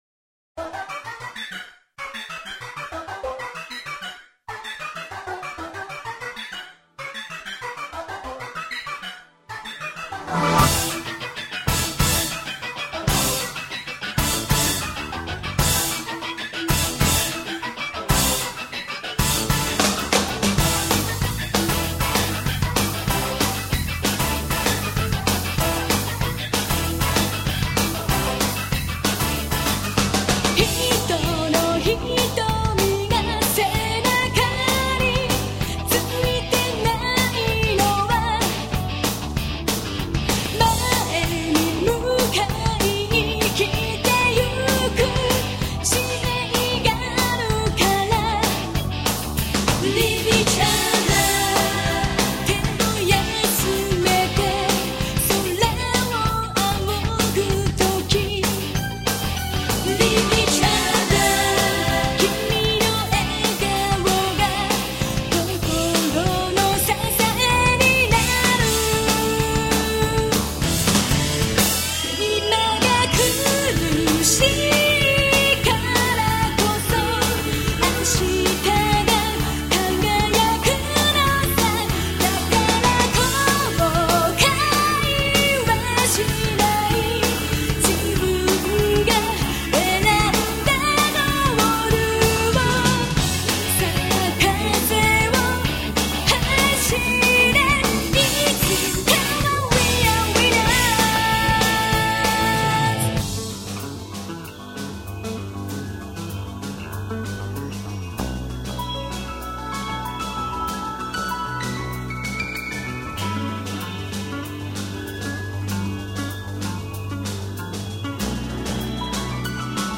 (오케스트라 사운드가 거의 사용되지 않았으며 기계적인 사운드에만 의존.)